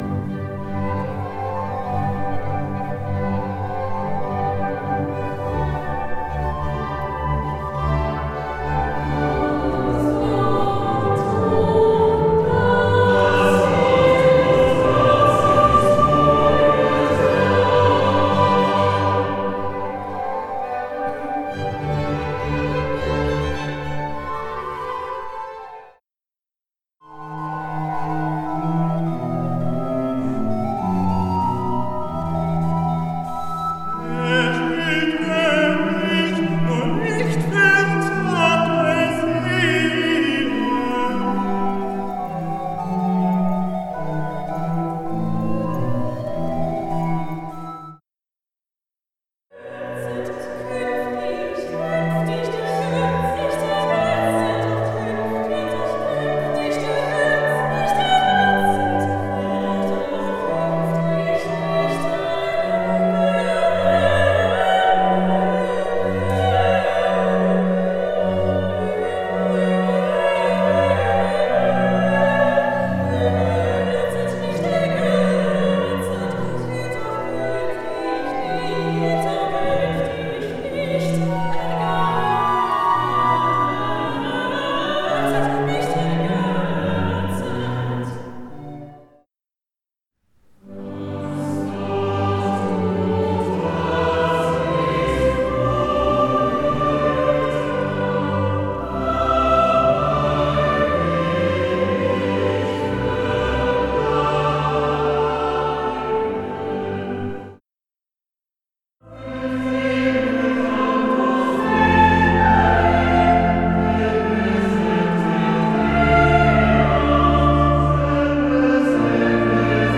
Wir leben weiter in der Freude der Auferstehung. Hören Sie hier noch einmal österliche Stimmen und Klänge, vom Konzert der Ostertage, aus unserer Kirche Sankt Antonius.